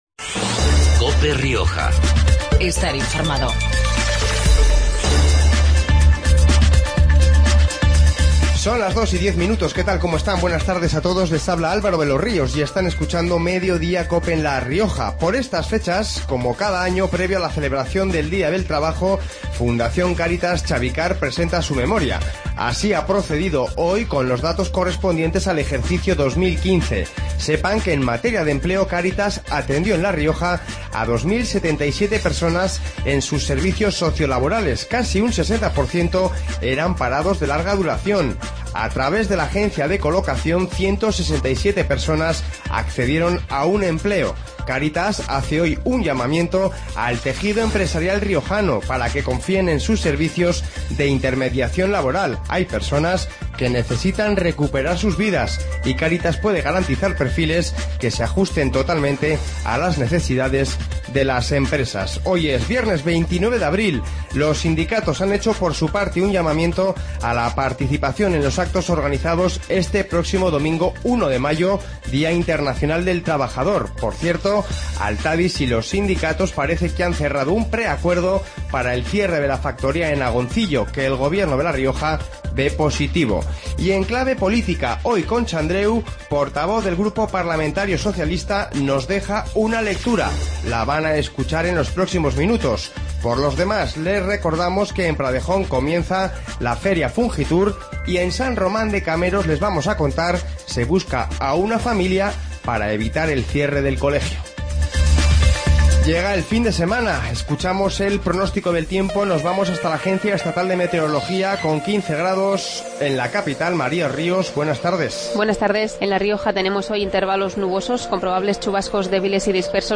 Informativo Mediodia en La Rioja 29-04-16